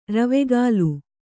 ♪ ravegālu